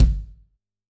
CYCdh_Kurz01-Kick03.wav